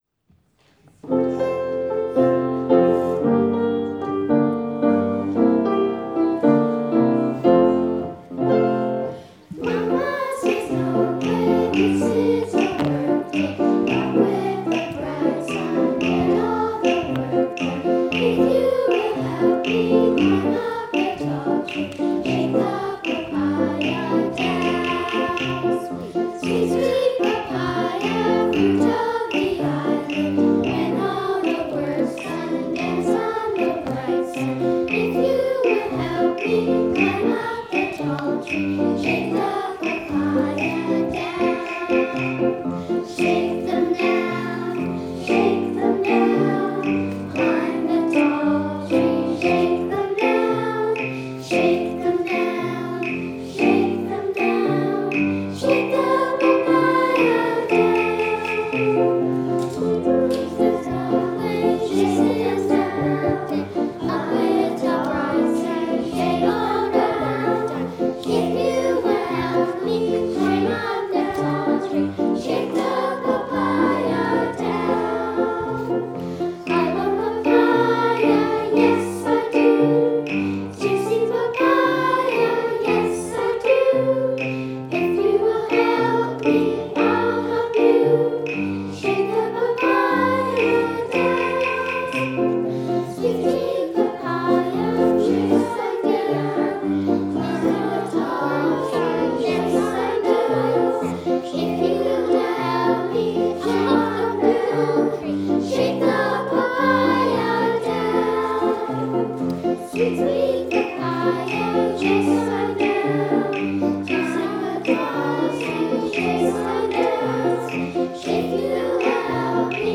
Recording Location: James Bay United Church, Victoria BC
Status: Raw, unedited
The 20-member children's chorus
128kbps Stereo